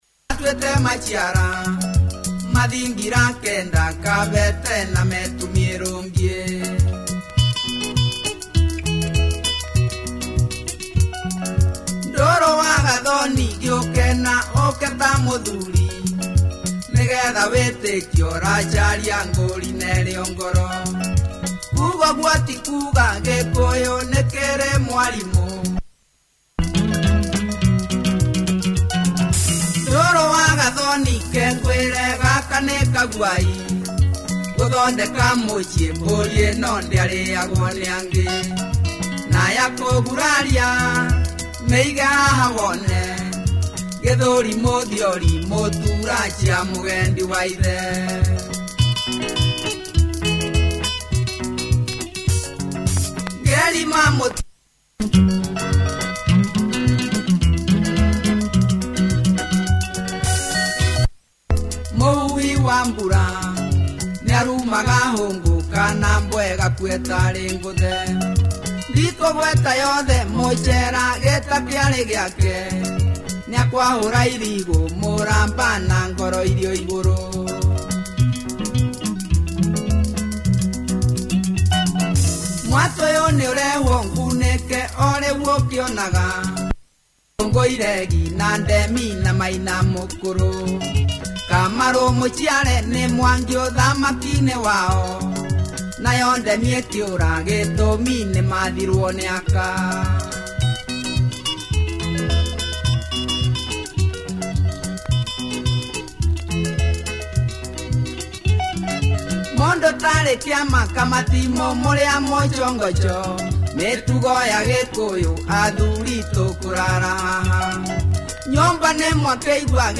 Nice Kikuyu Benga by this prolific group